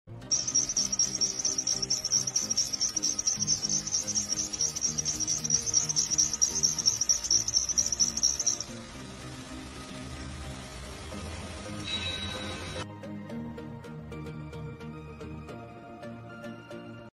🚘 Belt noise goes away sound effects free download
🚘 Belt noise goes away when warm | v belt noise